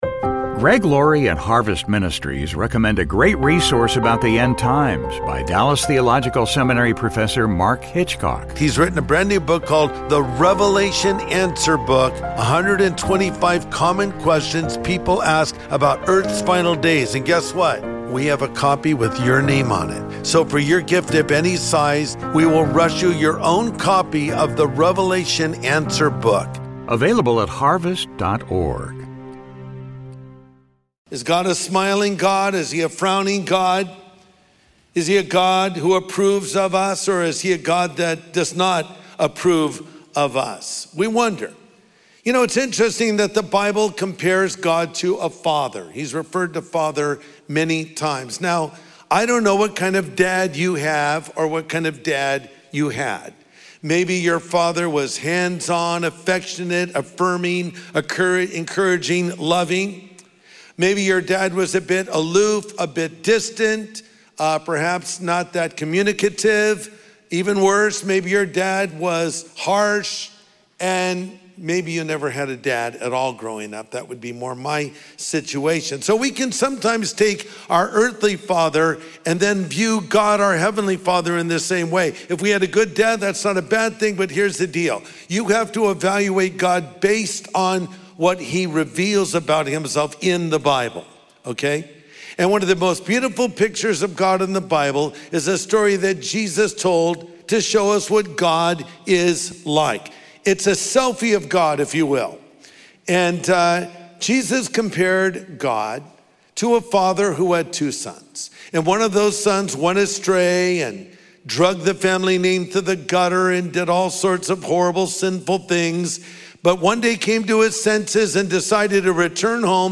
Pastor Greg Laurie delivers a message on the power of forgiveness.